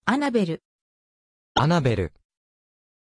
Pronuncia di Annabel
pronunciation-annabel-ja.mp3